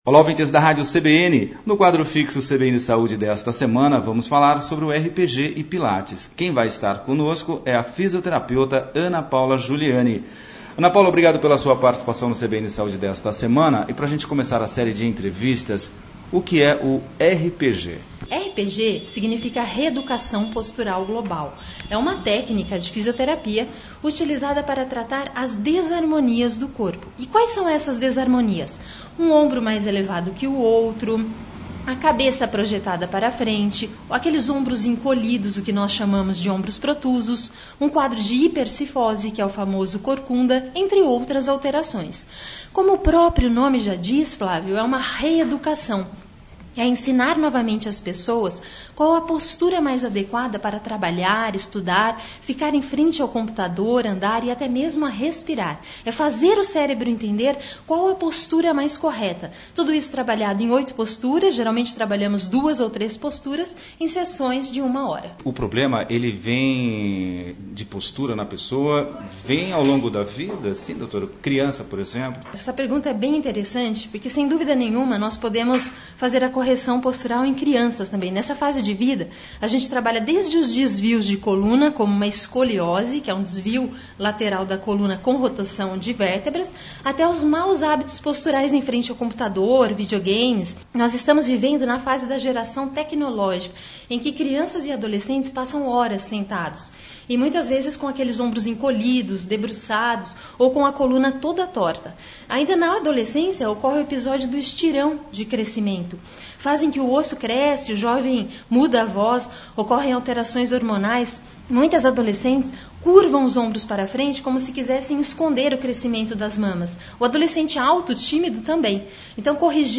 A Entrevista foi ao ar no dia 22 de Abril de 2019